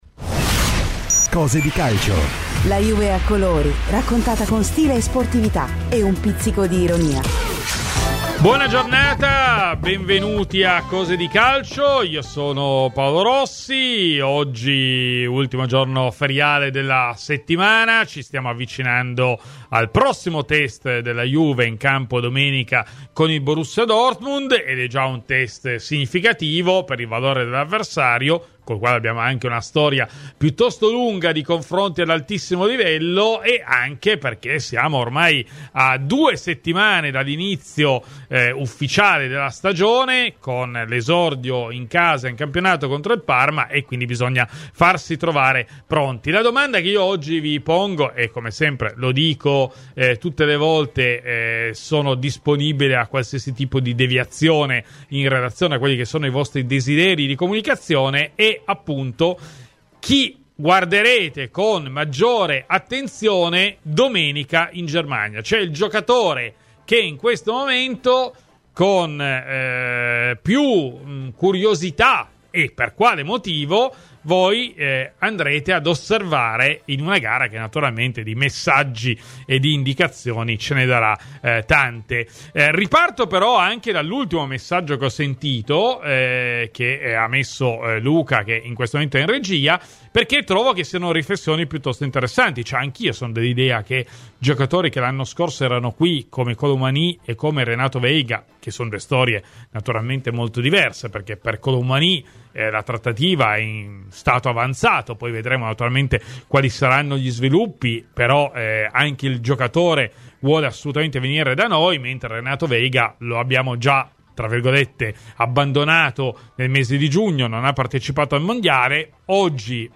in diretta da Herzogenaurach